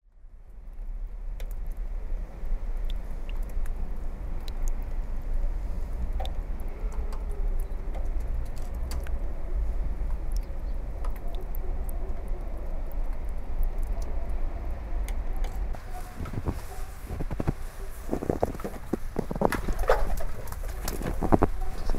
Звуки города
Звуки зимнего города: городской шум под снежным покровом